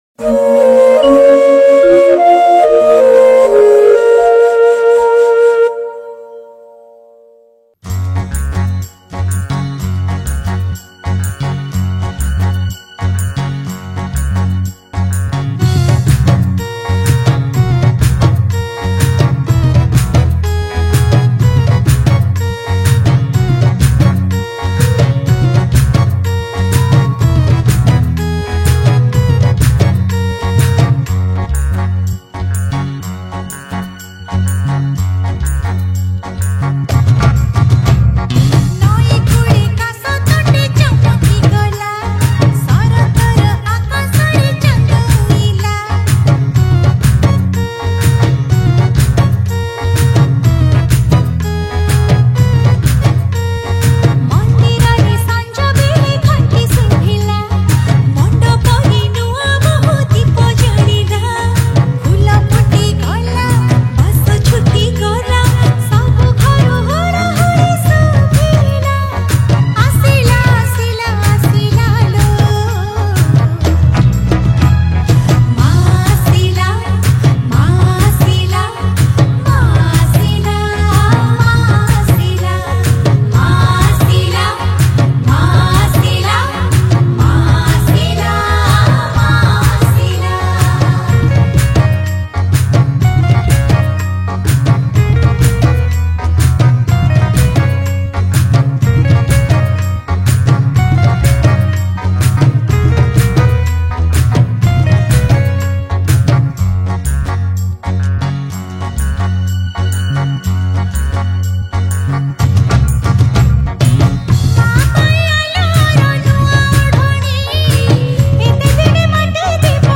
Durga Puja Special Song 2024 Songs Download